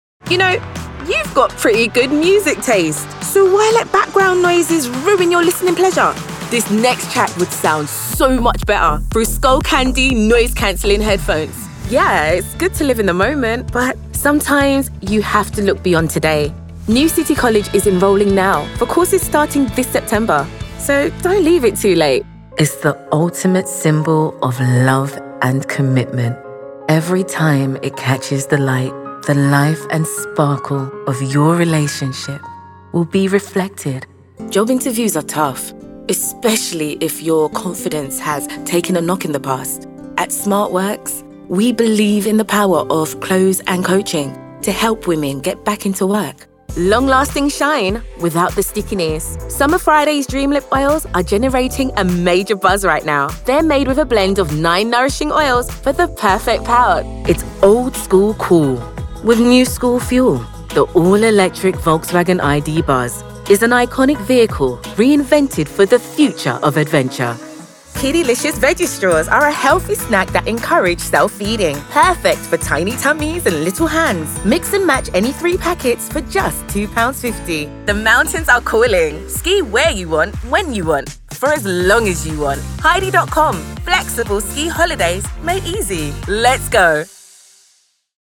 Engels (Brits)
Natuurlijk, Opvallend, Speels
Commercieel